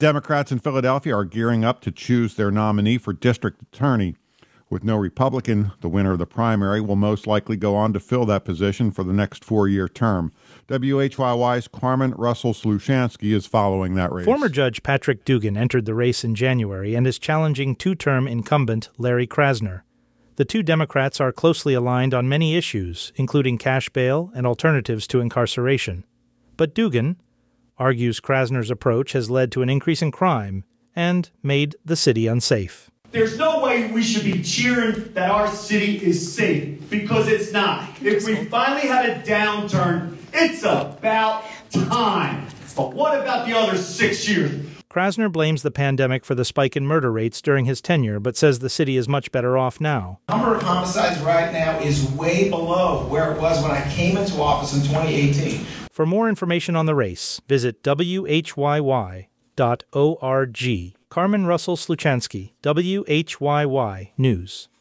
It was all applause lines for GOP presidential candidate Ted Cruz as he stopped in central Pennsylvania to speak at a conference for political conservatives.